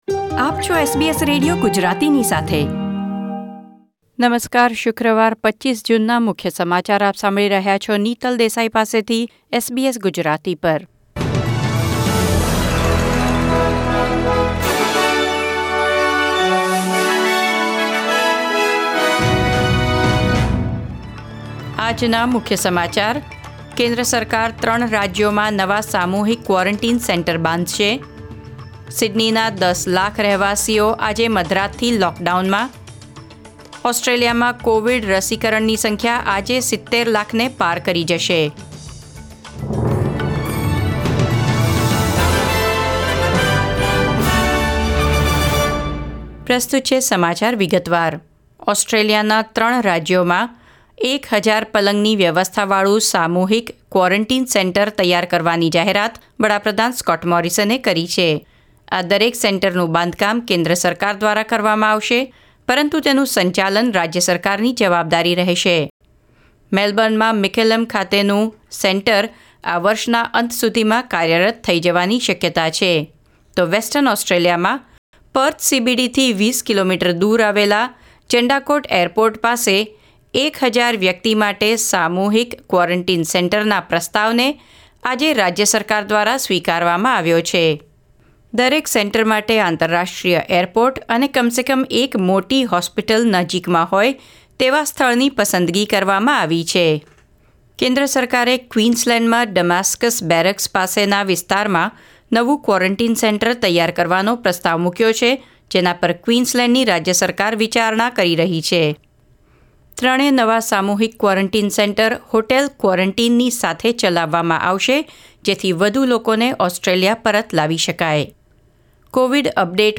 SBS Gujarati News Bulletin 25 June 2021